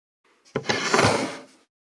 387,机の上をスライドさせる,スー,ツー,サッ,シュッ,スルッ,ズズッ,スッ,コト,トン,ガタ,ゴト,カタ,
効果音